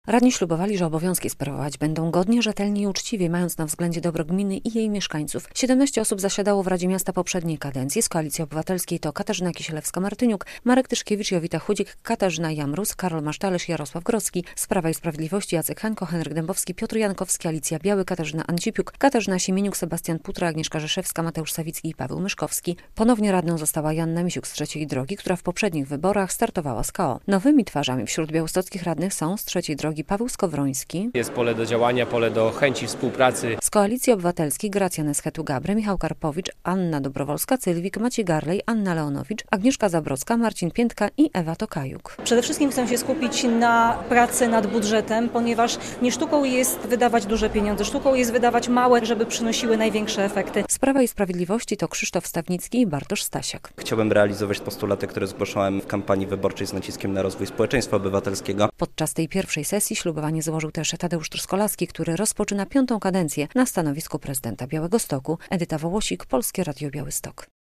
Radni odebrali zaświadczenia o wyborze i złożyli ślubowanie - relacja